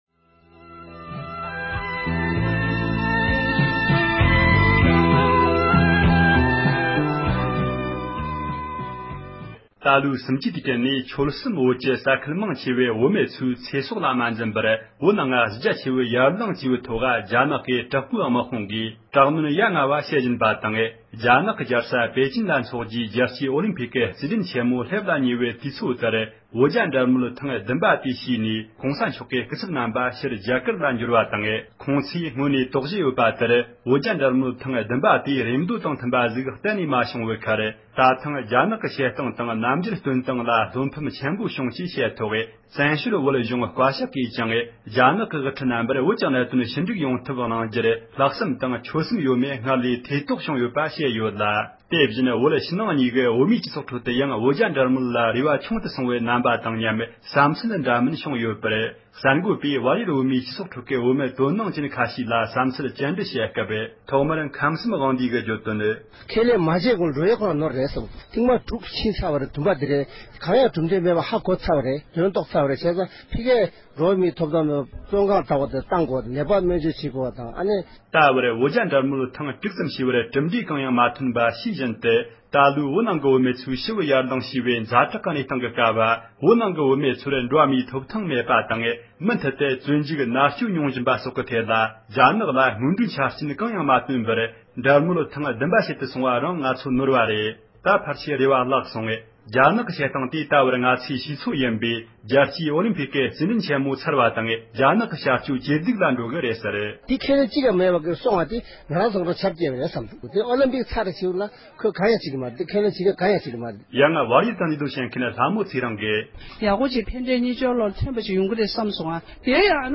བོད་རྒྱའི་གྲོལ་་མོལ་ཐད་བཙན་བྱོལ་བོད་མི་ཁག་ཅིག་ལ་བཀའ་འདྲི་ཞུས་པ།
སྒྲ་ལྡན་གསར་འགྱུར།